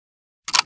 kar98k_reload.ogg